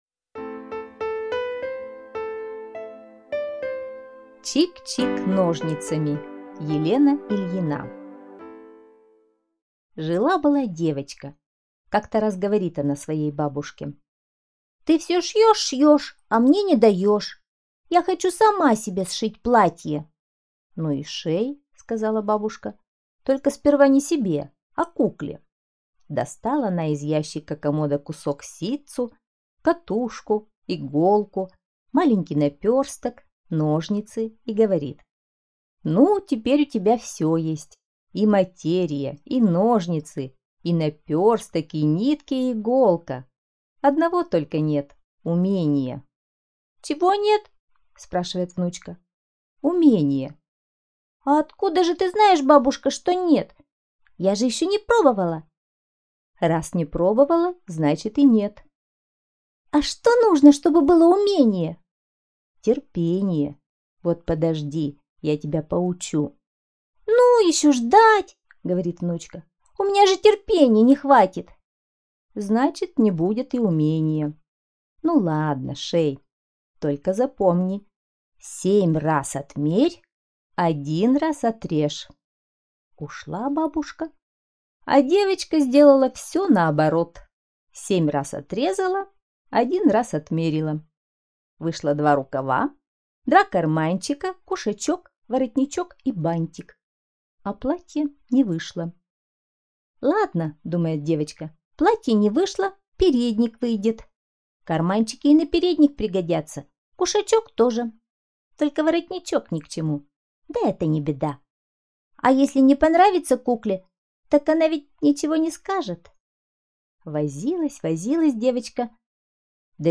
Аудиорассказ «Чик-чик ножницами»